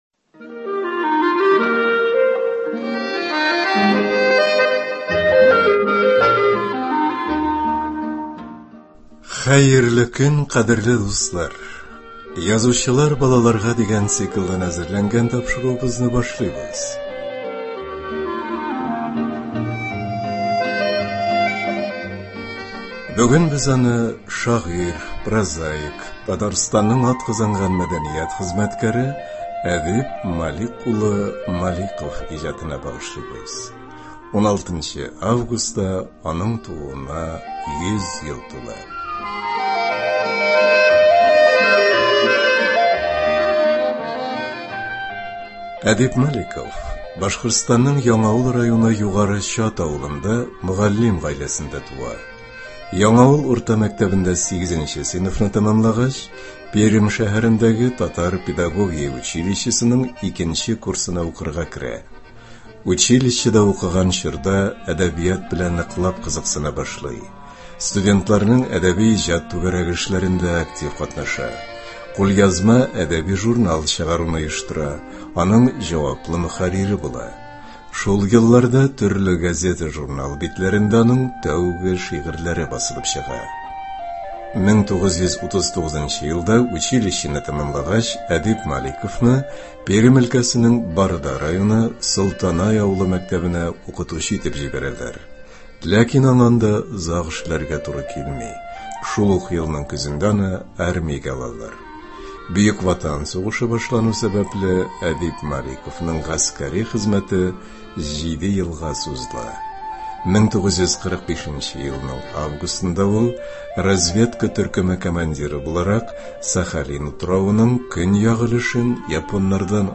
Радиобыз фондыннан авторның укуында шигырьләре, истәлекләре яңгырый.